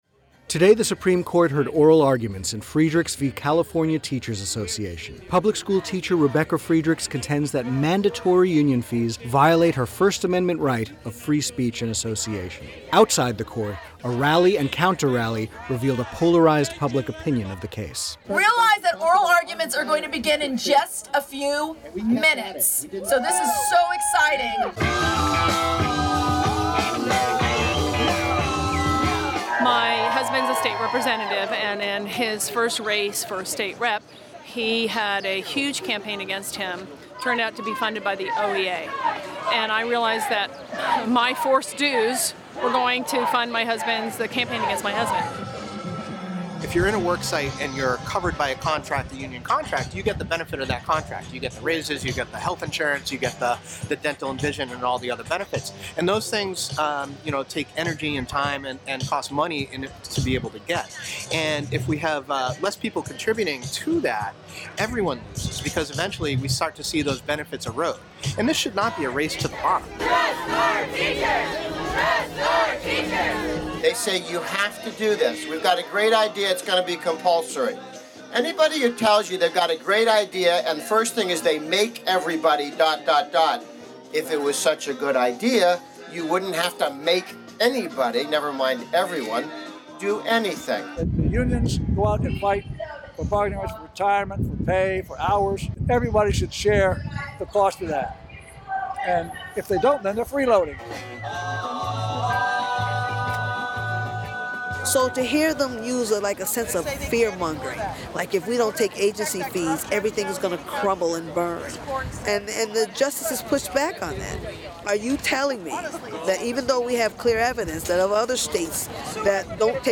With a few hundred protesters from both sides crowded together on the steps of the Court, speakers attempted to out-shout and out-chant each other in the public square.